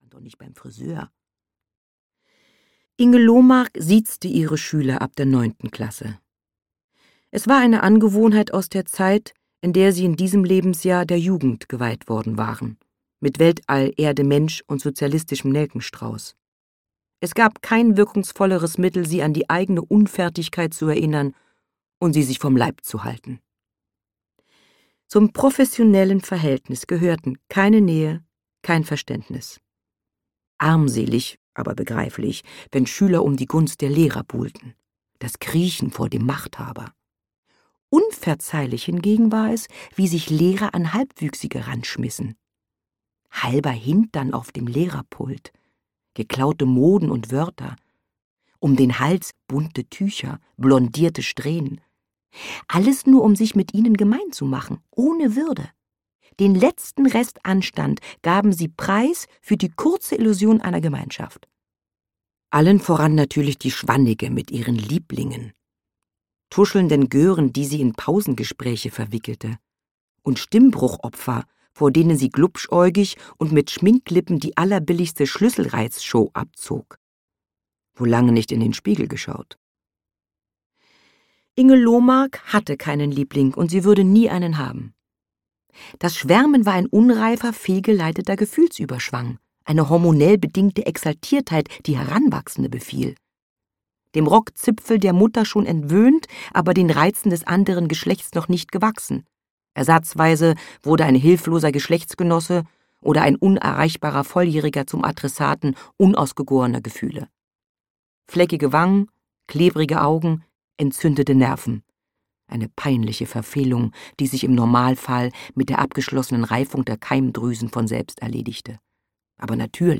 Der Hals der Giraffe - Judith Schalansky - Hörbuch